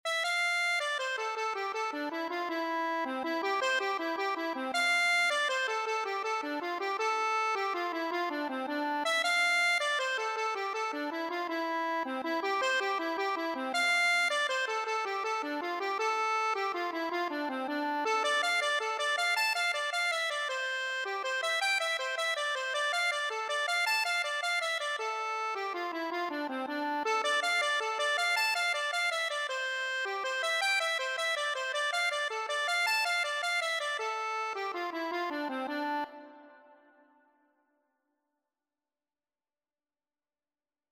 6/8 (View more 6/8 Music)
Accordion  (View more Intermediate Accordion Music)
Traditional (View more Traditional Accordion Music)